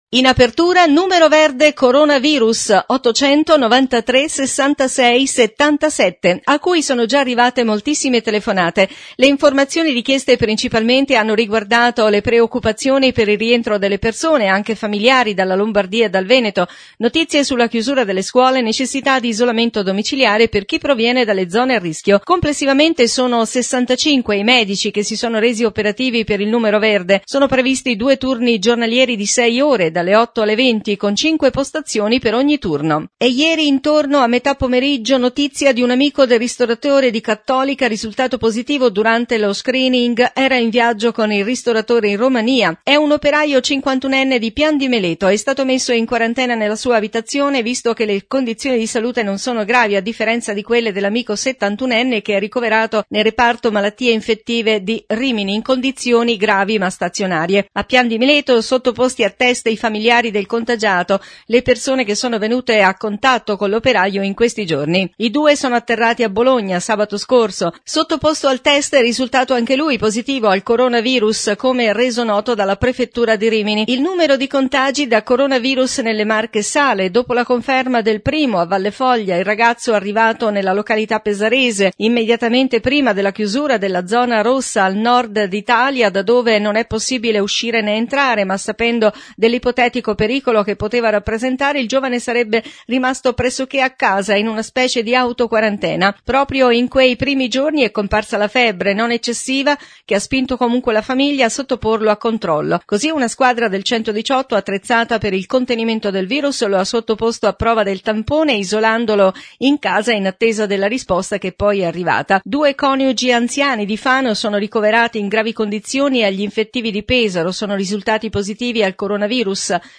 Interviste .Luca Ceriscioli – Presidente Regione Marche